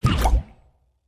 tt_s_ara_cfg_propellerBreaks.mp3